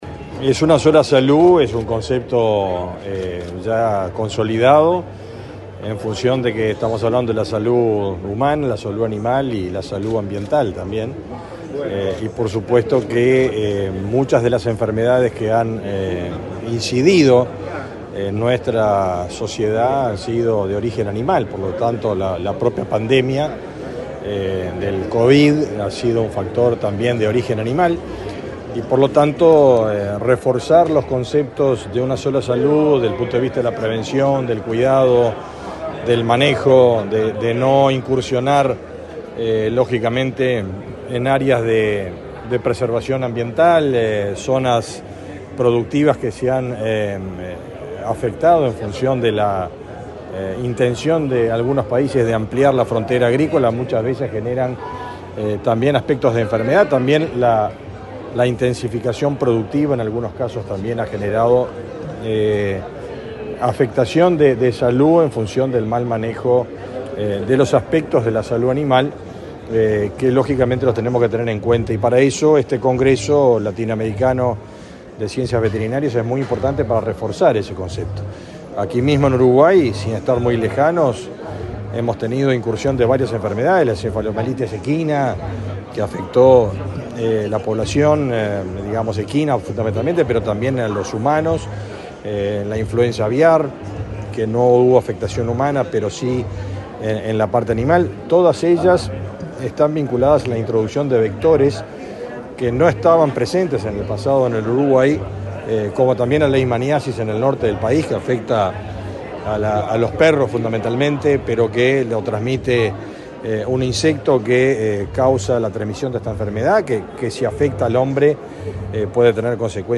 Declaraciones del ministro de Ganadería, Fernando Mattos
El ministro de Ganadería, Fernando Mattos, dialogó con la prensa, luego de participar, este martes 3 en Montevideo, en el XXVII Congreso Panamericano